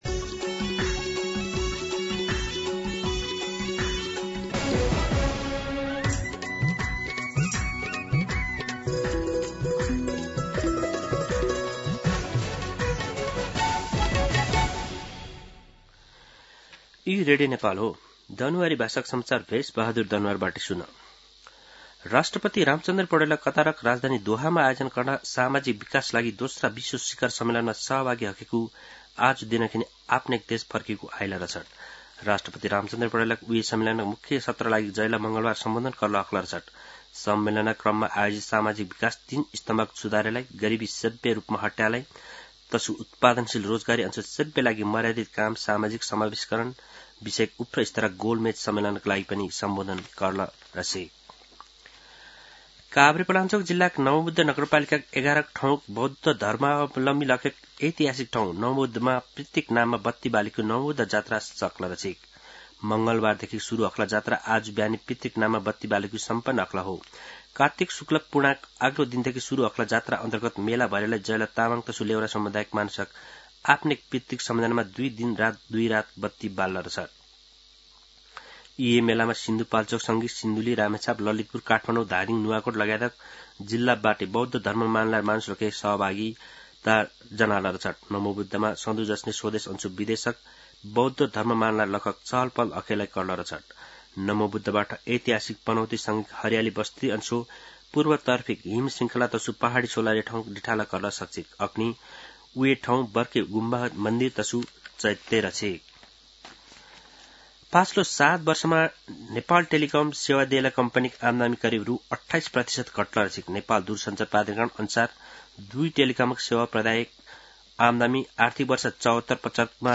दनुवार भाषामा समाचार : २० कार्तिक , २०८२